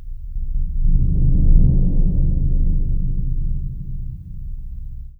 BIG BD 2A -L.wav